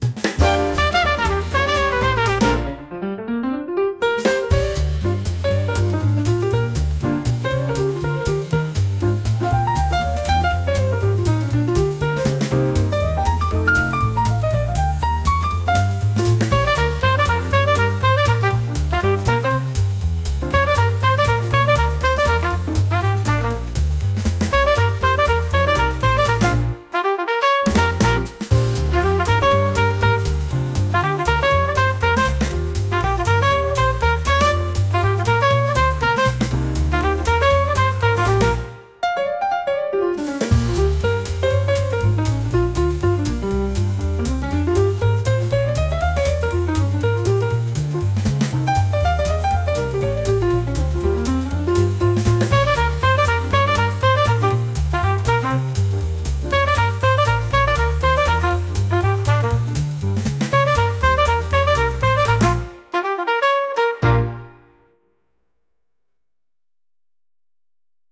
SUNO에서 음악을 생성